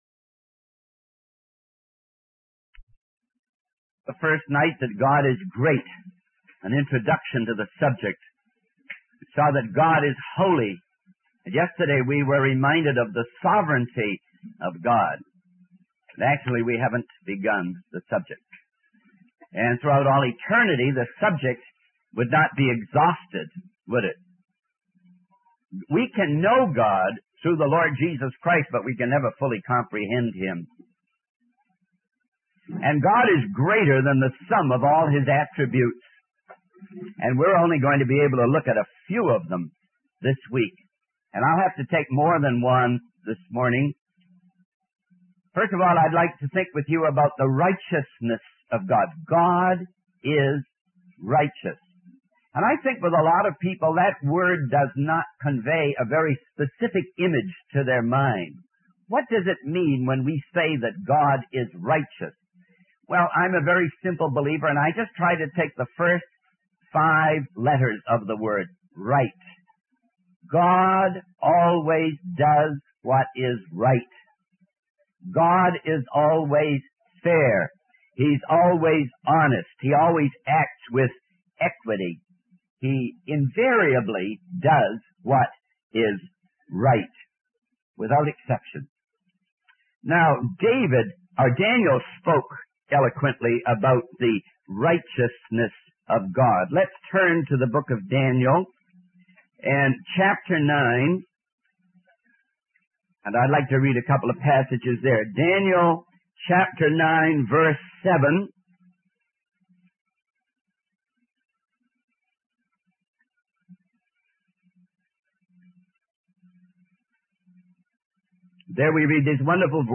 In this sermon, the speaker emphasizes the importance of worshiping God for his unchangeable character and faithfulness. He highlights the need for believers to bear the family resemblance of God by being consistent and not fickle or moody.